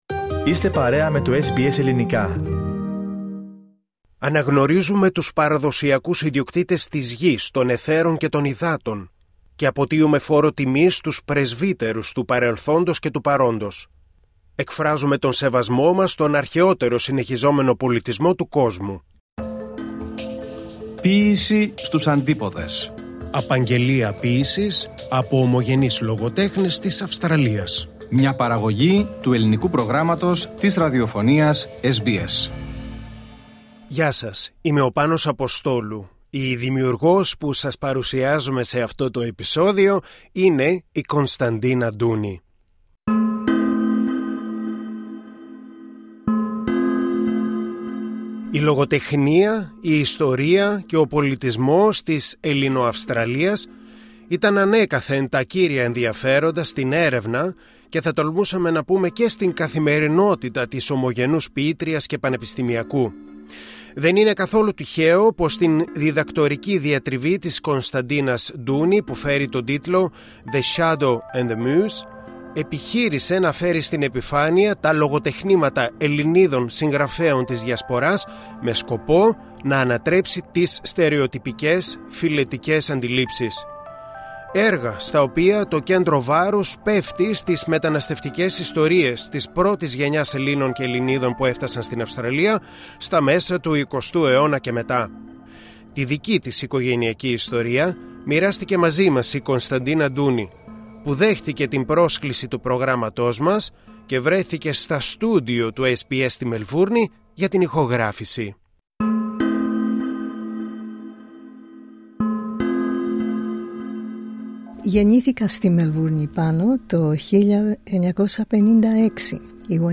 Ποίηση στους Αντίποδες